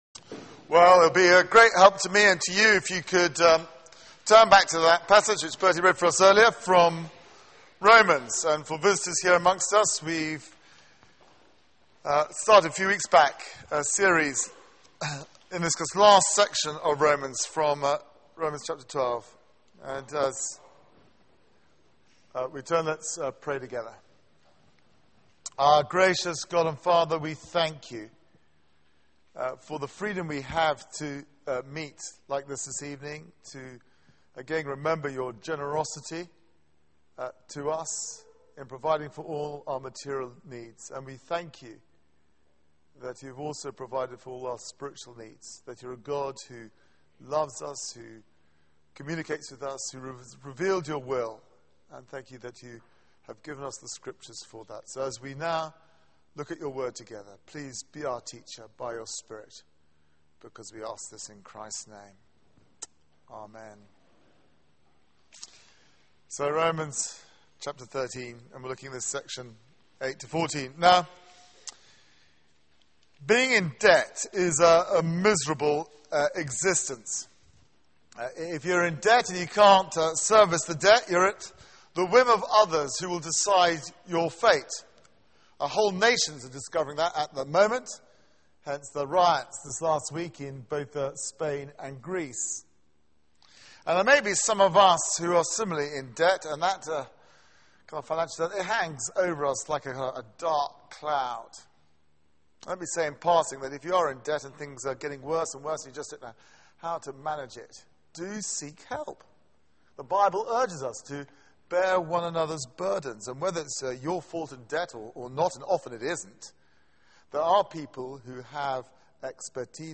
Media for 6:30pm Service on Sun 30th Sep 2012 18:30 Speaker
Passage: Romans 13:8-14 Series: The Christian Life Theme: The love of life Sermon